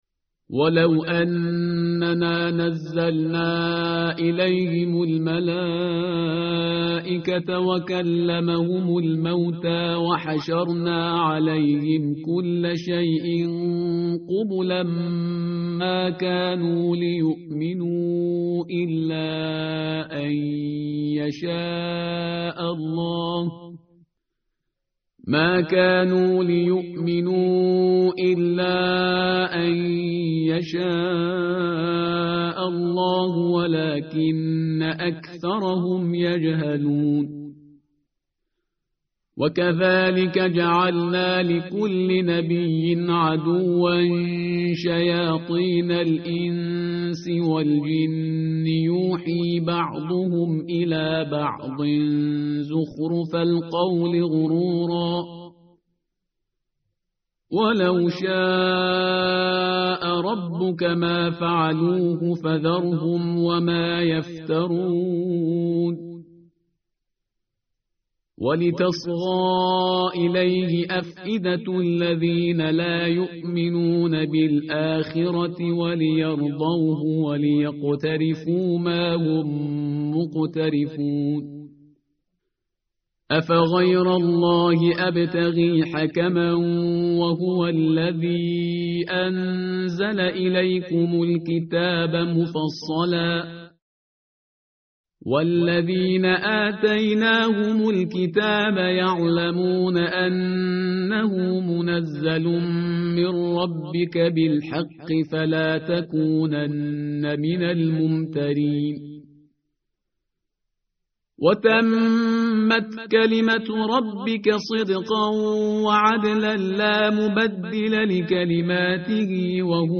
tartil_parhizgar_page_142.mp3